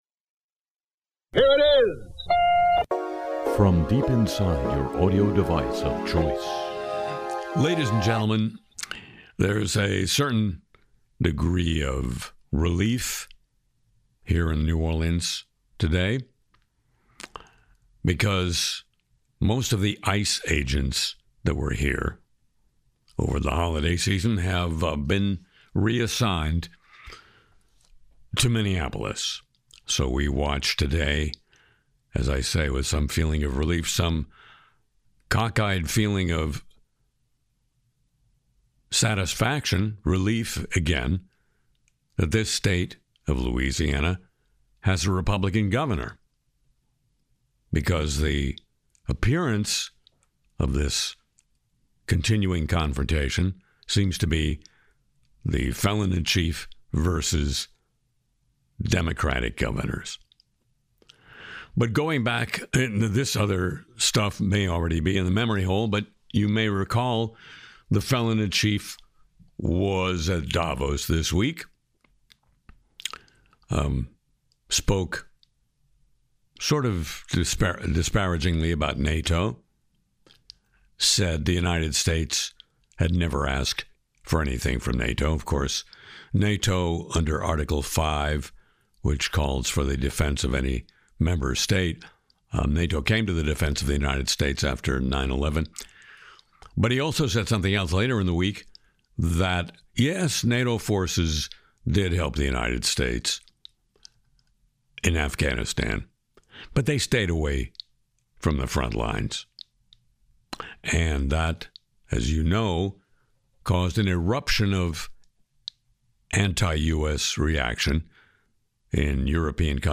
Harry Shearer sings “My Psychology,” skewering Trump’s Greenland talk, then dives into AI project failures, Crypto Winter, Oracle cameras, and microplastics in the air.